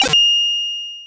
Retro Video Game Blip 3.wav